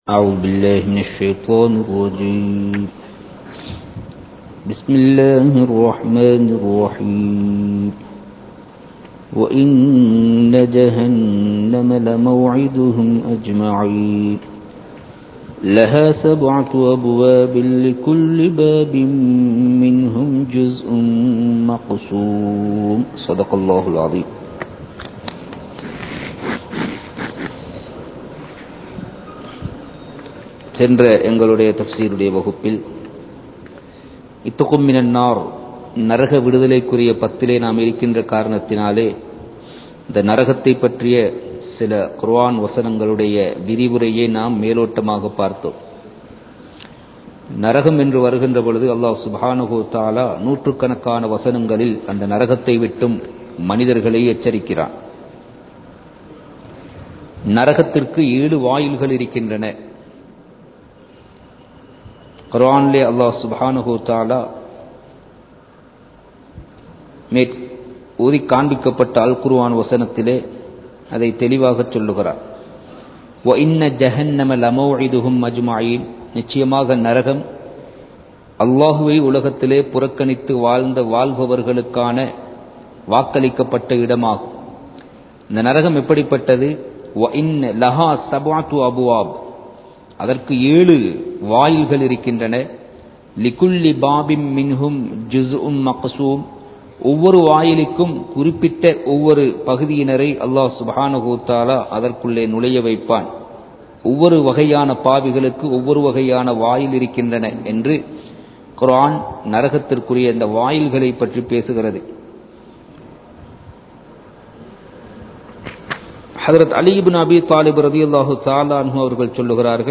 நரகங்களும் வேதனைகளும் | Audio Bayans | All Ceylon Muslim Youth Community | Addalaichenai